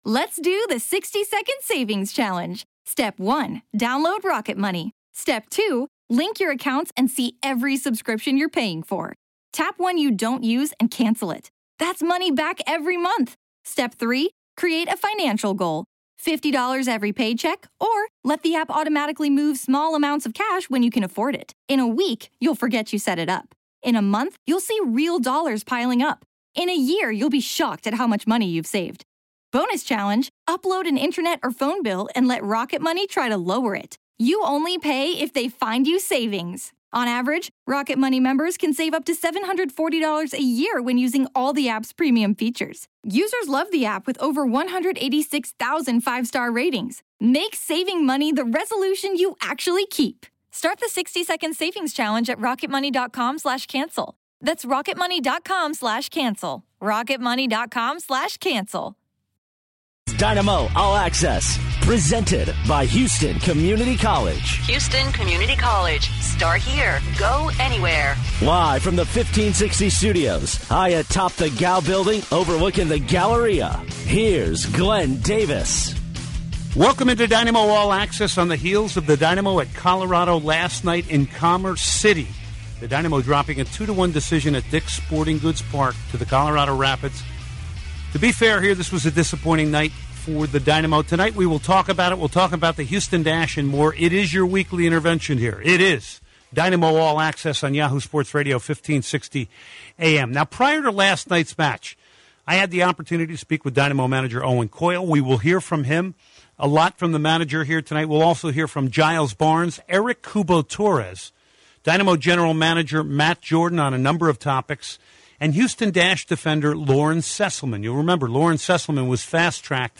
then goes on to interview head coach Owen Coyle. They discuss the desire to have a vocal leader on the field, how the Argentina vs. Bolivia game can be a good thing for the league, Giles Barnes's form, amongst other things.